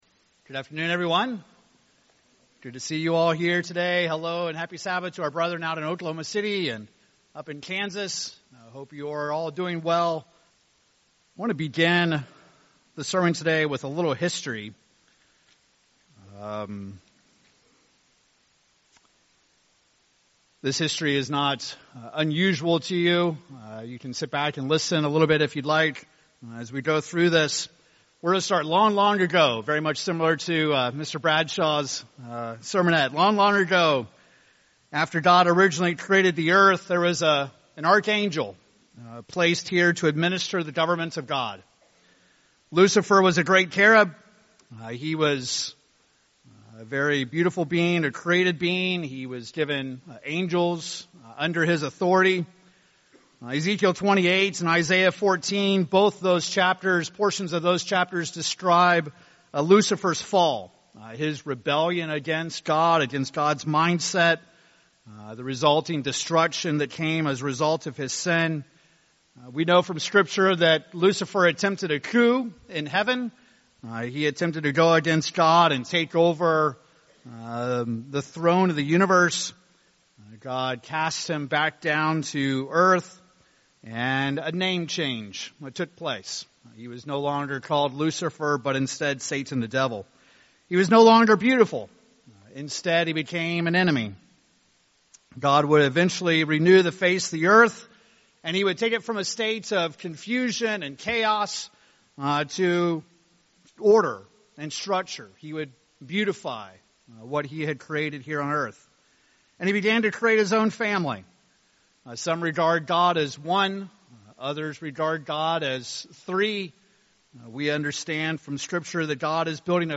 This sermon continues our series on the topic of Righteousness.
Given in Oklahoma City, OK Salina, KS Tulsa, OK Wichita, KS